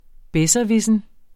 Udtale [ ˈbεsʌˌvisən ]